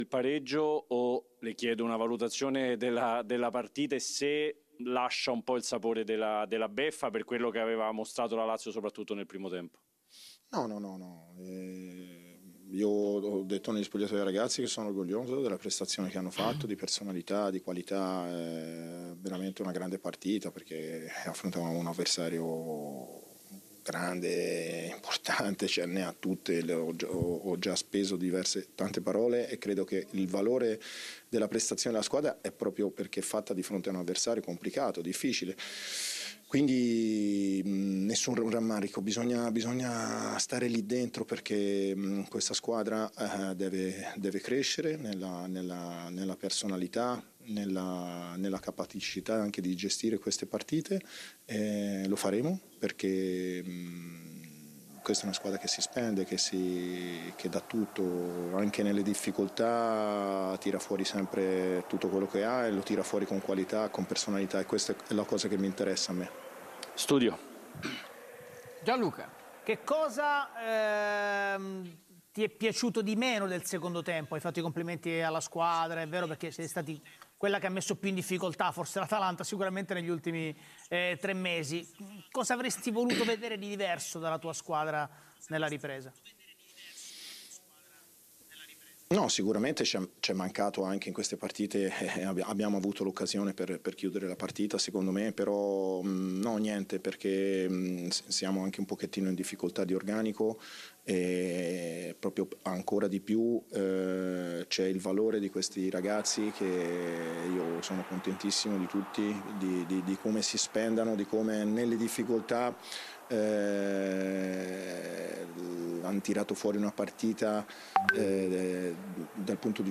Senti Baroni nel postpartita